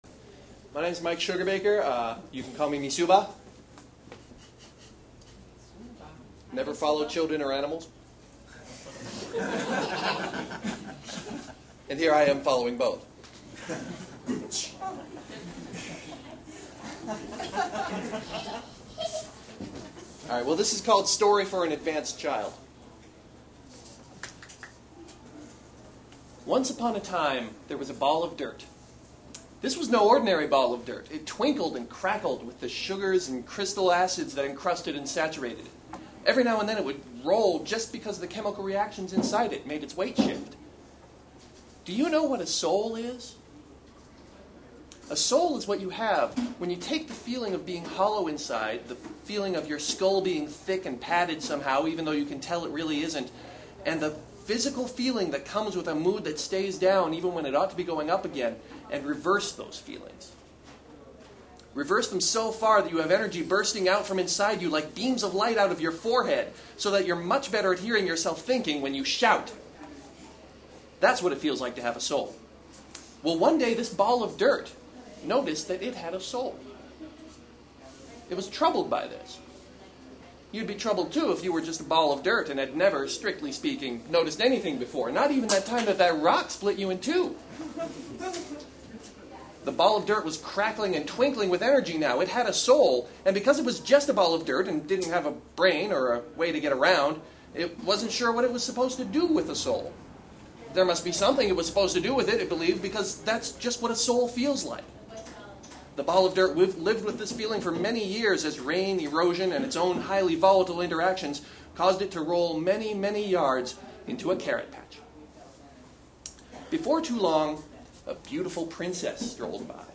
However, since they were actually physically present with each other, they decided to read to each other some of what they wrote out loud. This happened at an IWW union hall in southeast portland on Saturday night.